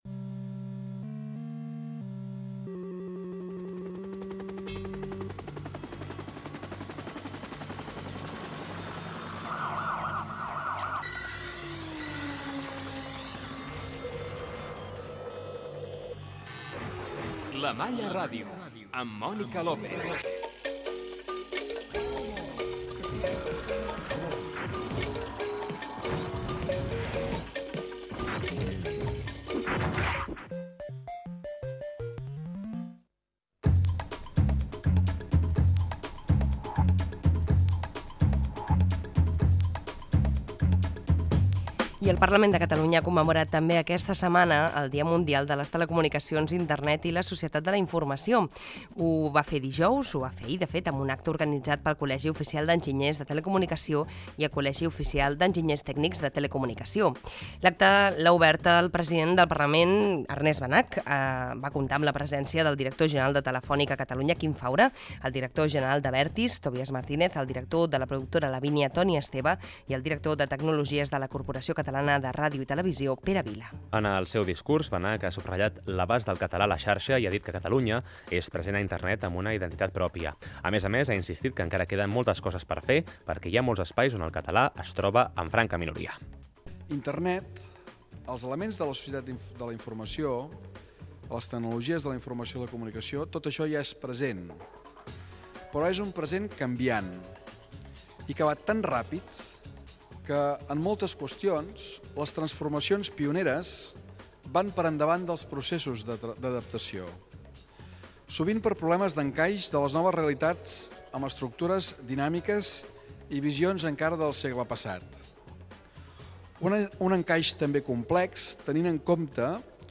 Careta del programa, presentació. Debat al Parlament de Catalunya amb motiu del Dia Mundial de les Telecomunicacions.
Divulgació
Fragment extret de l'arxiu sonor de COM Ràdio